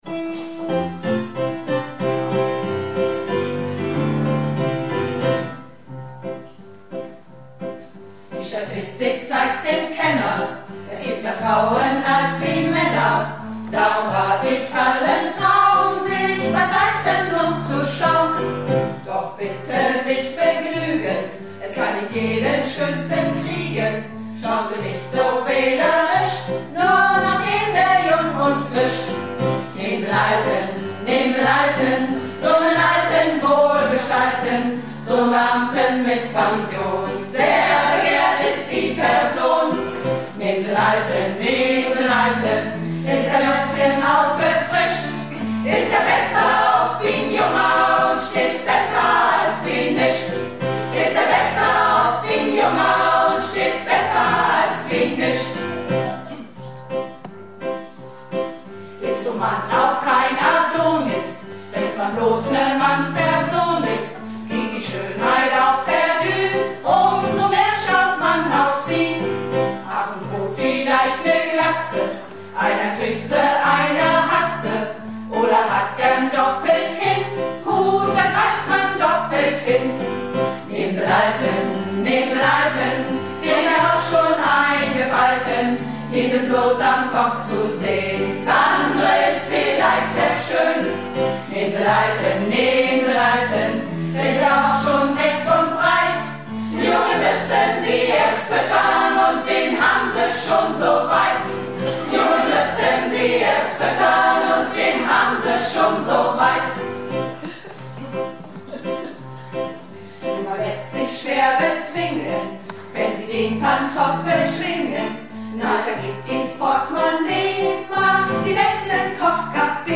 Theater - "Du musst es wagen - Sünner Tegenstöten word nümms deftig" am 23.09.12 in Filsum